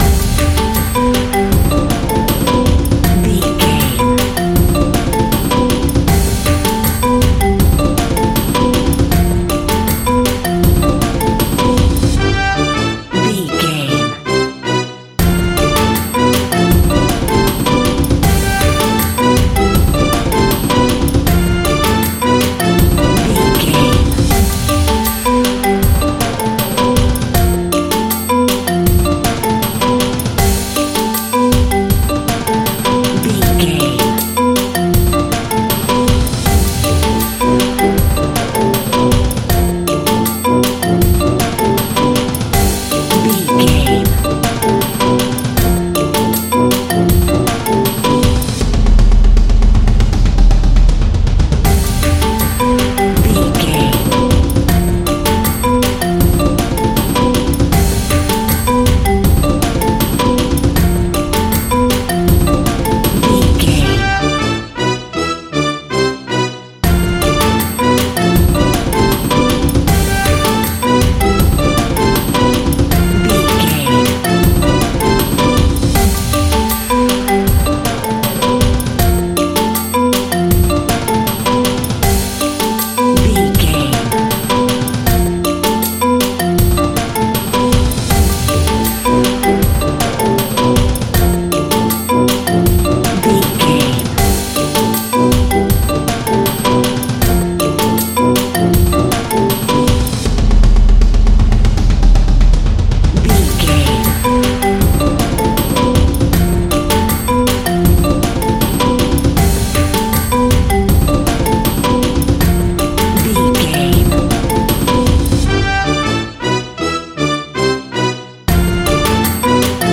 Mixolydian
ethnic percussion